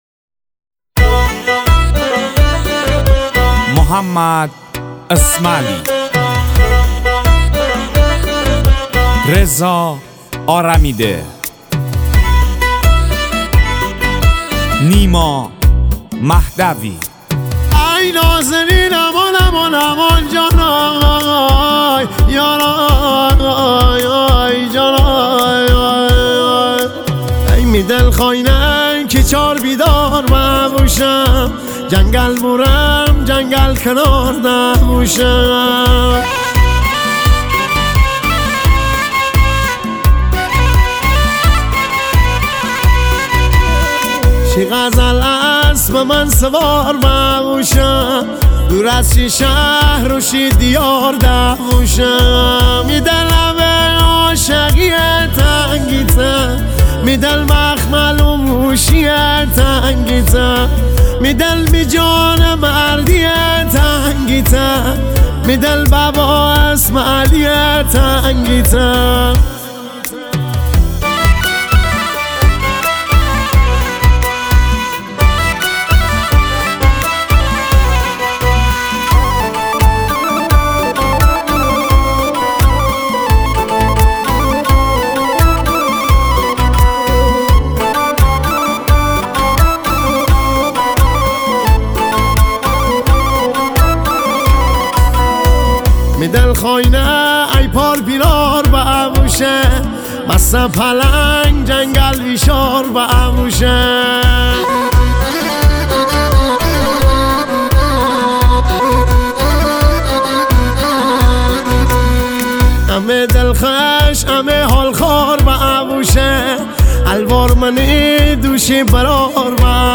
غمگین
ریتمیک